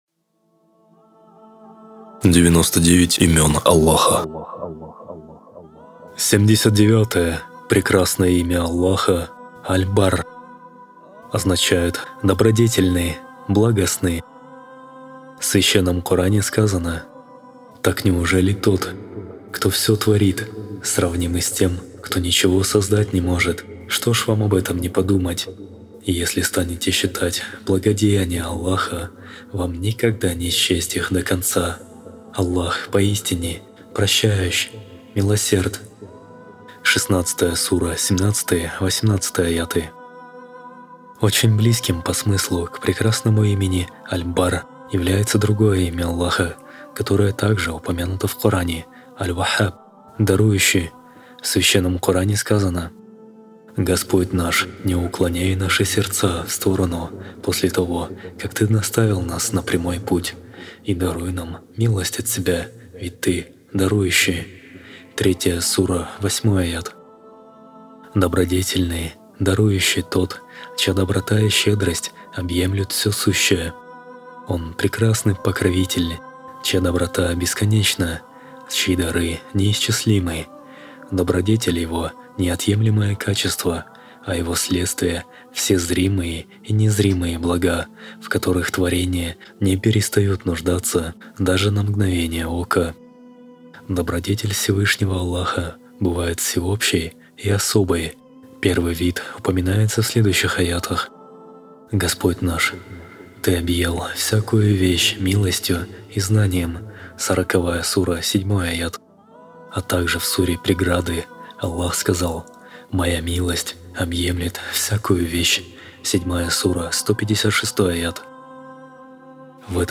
99 имён Аллаха - Циклы уроков